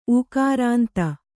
♪ ūkārānta